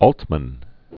(ôltmən), Robert 1925-2006.